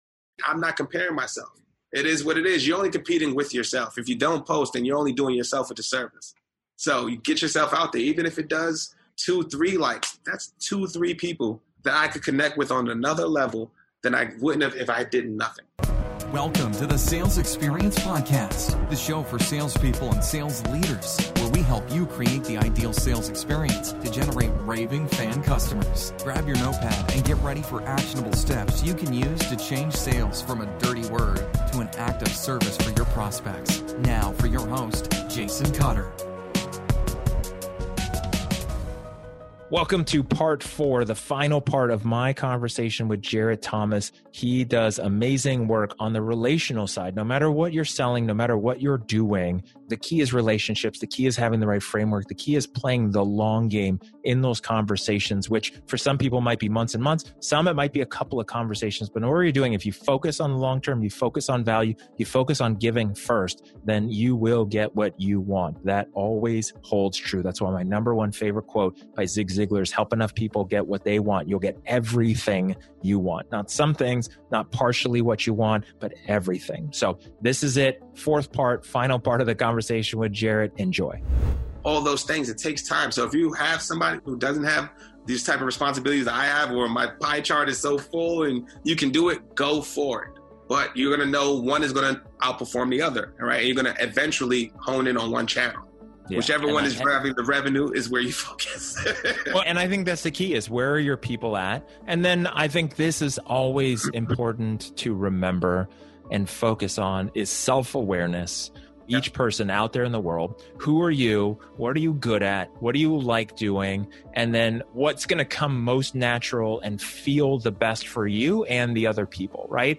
Instead of making 100’s of calls a day, make less, then reach out and connect human to human. Check out this final part of our conversation.